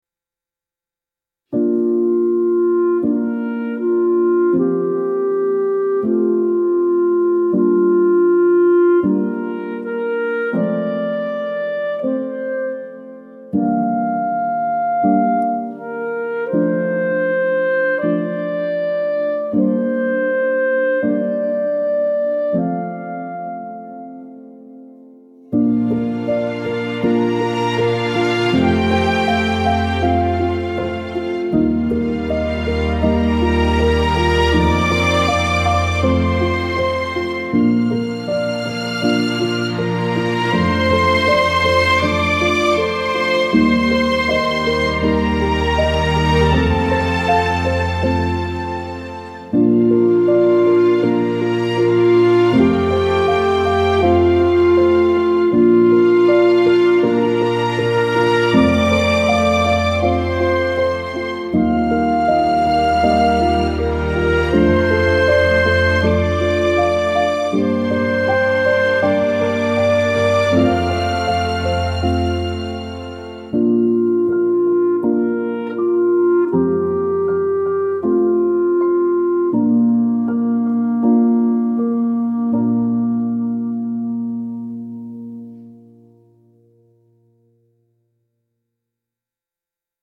warm vintage film score with clarinet, strings and gentle resolution